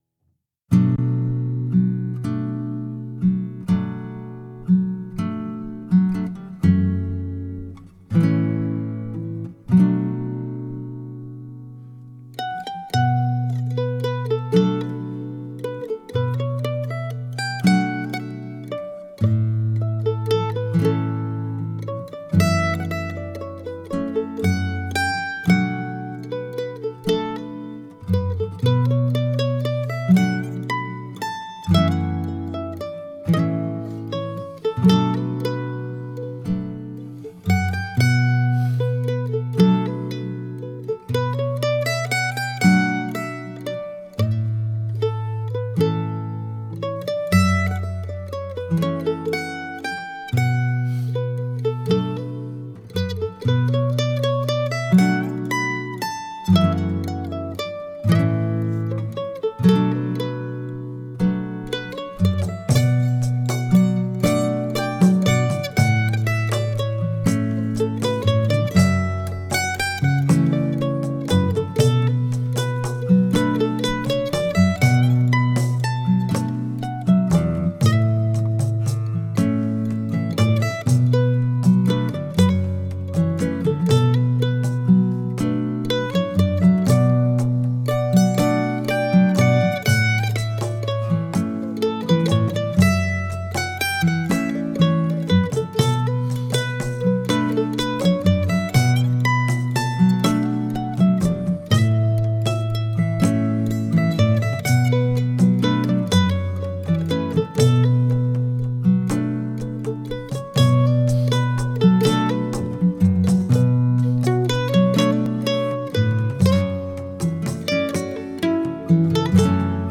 Genre: Jazz, World, Accordion